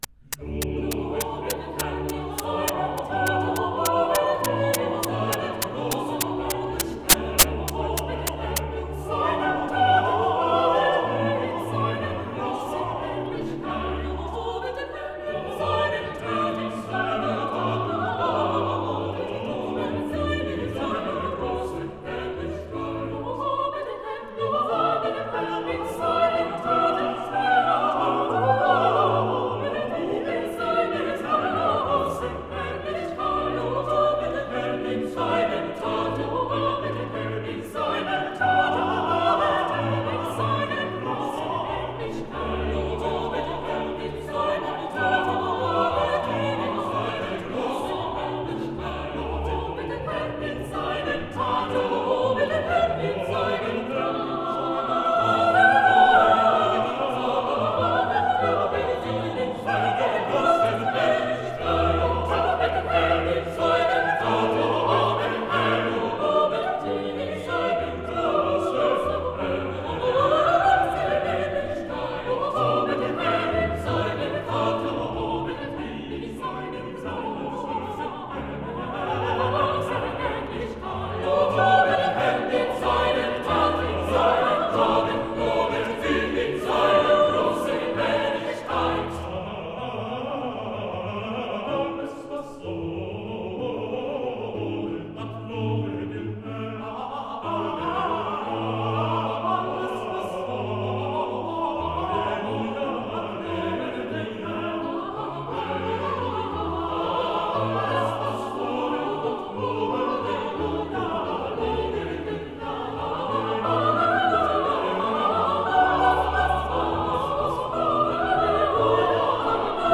Motet for Eight Voices in double Choir.